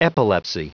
Prononciation audio / Fichier audio de EPILEPSY en anglais
Prononciation du mot epilepsy en anglais (fichier audio)